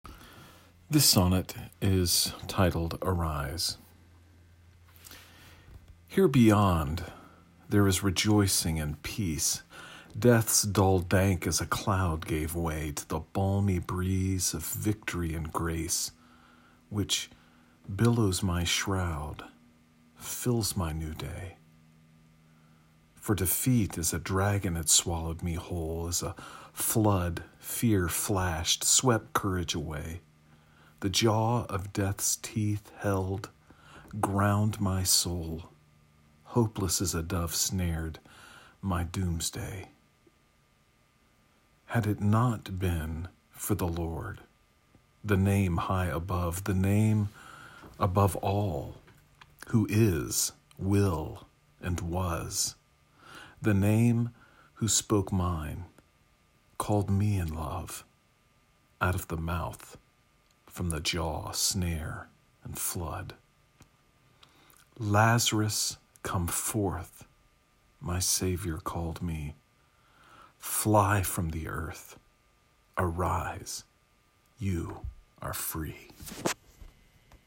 You may listen to me read the sonnet via the player below.